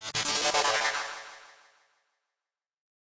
snd_electric_talk.wav